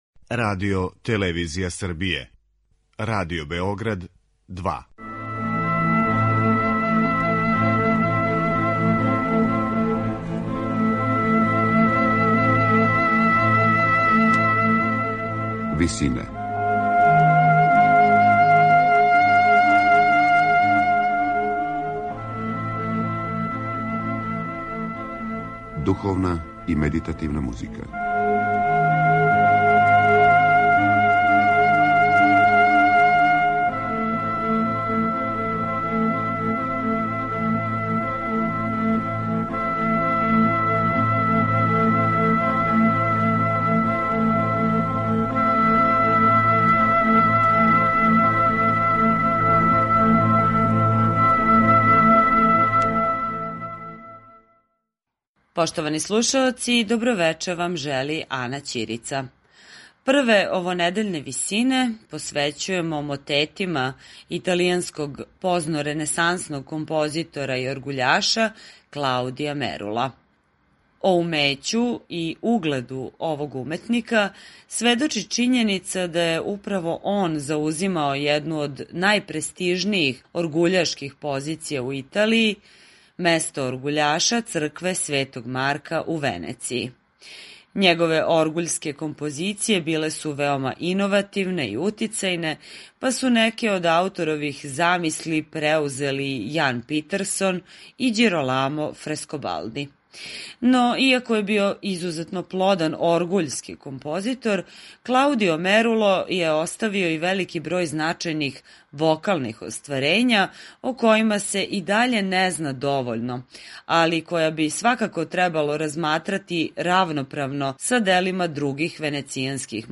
Мотети